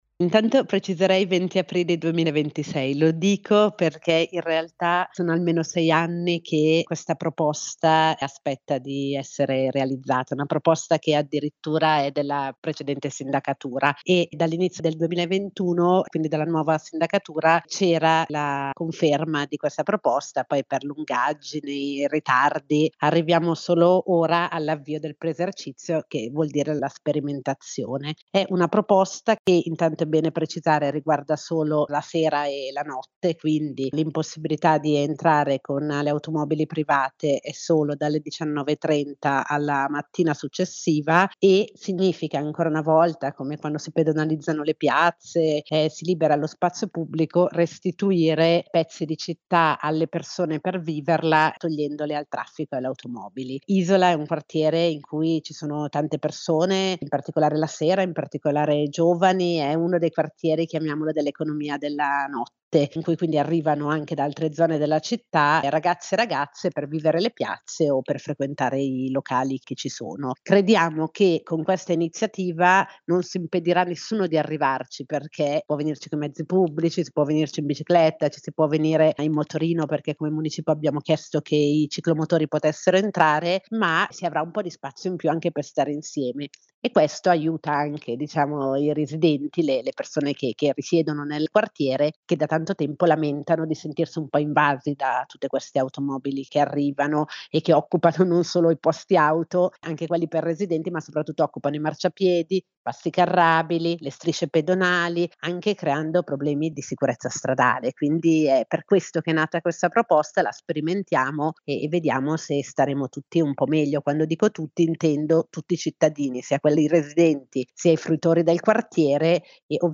Ascolta l'intervista ad Anita Pirovano, presidente del Municipio 9 di Milano.